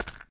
coin4.ogg